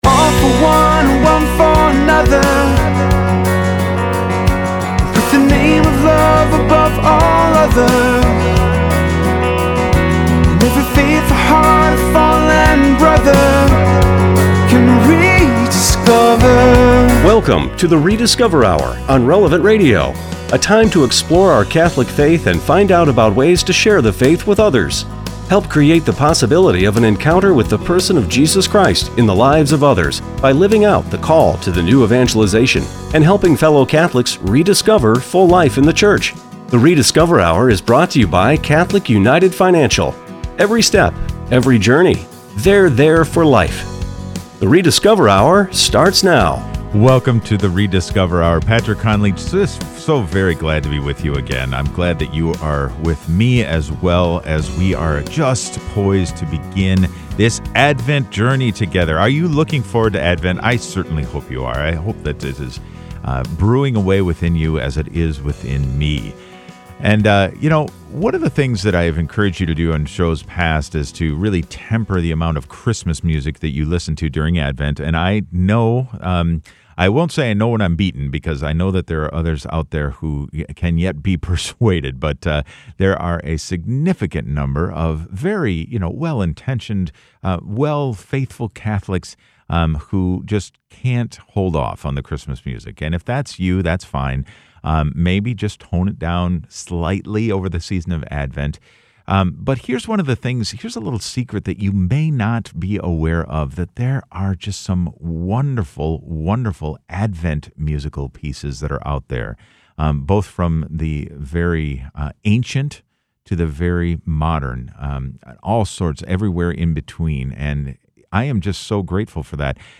Sunday, December 2 is the first day of Advent! Join us on the Rediscover: Hour for our Advent music show.